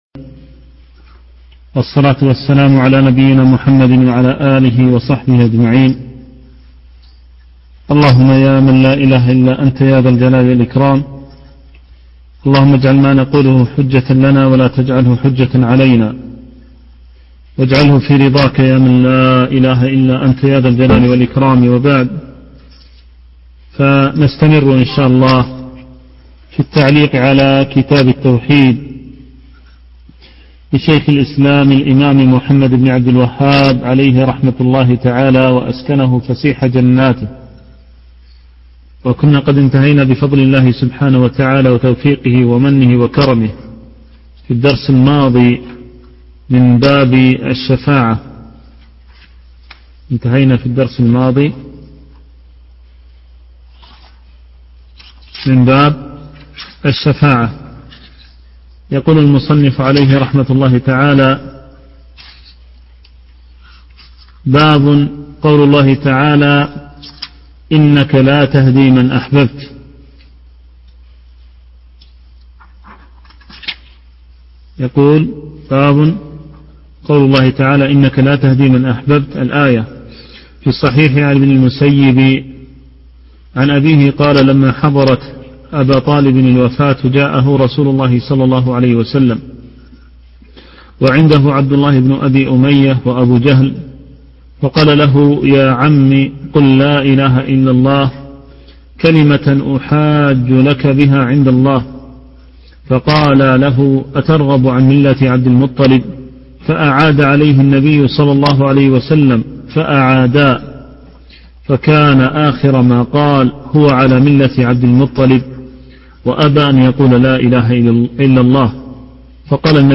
شرح كتاب التوحيد - الدرس الثامن عشر
كتاب التوحيد - الدرس الثامن عشر.mp3